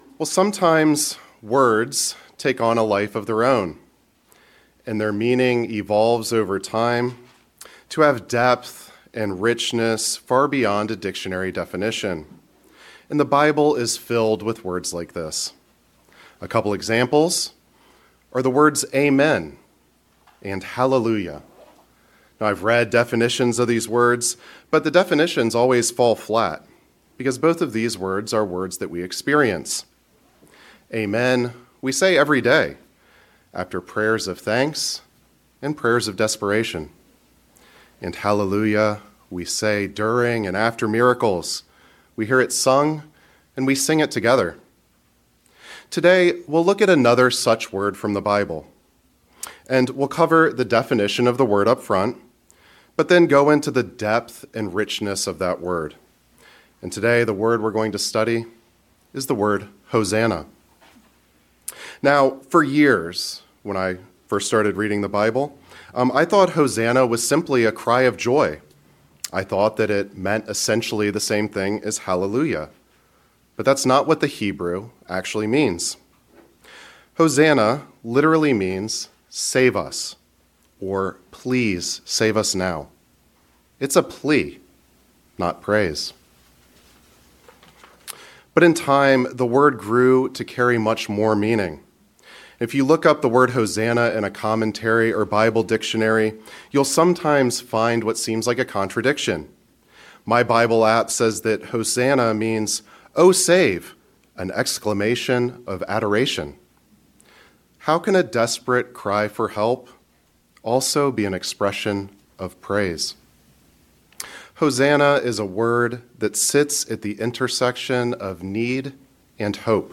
This sermonette explores the word Hosanna in its biblical context and shows how a cry for deliverance became an expression of hope-filled faith in God’s saving power.
Given in Northern Virginia